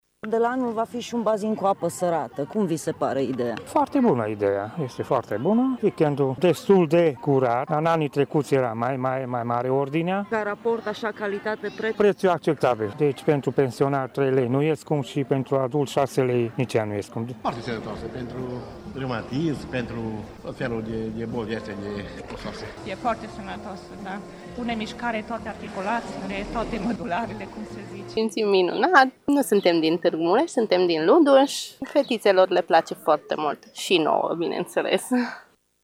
Tîrgumureşenii s-au arătat încântaţi de idee: